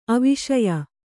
♪ aviṣaya